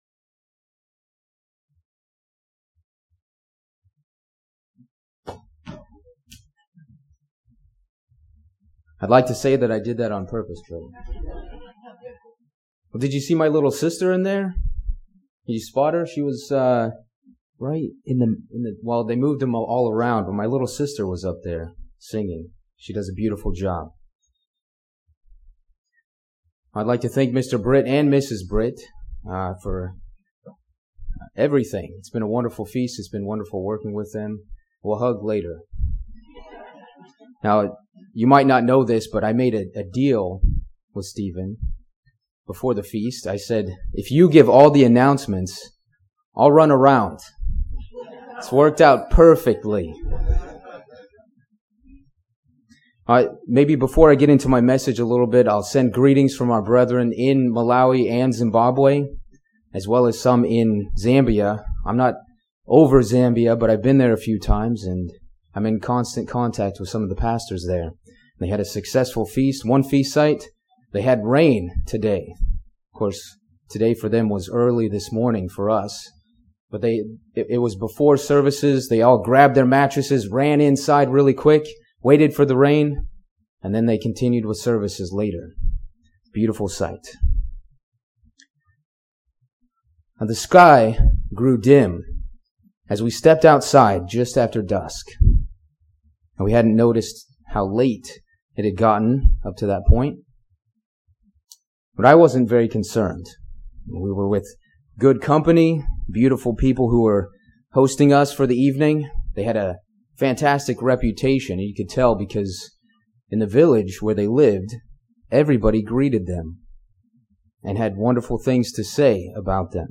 This sermon was given at the Lancaster, Pennsylvania 2020 Feast site.